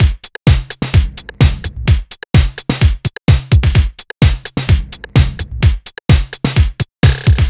Retro Drums.wav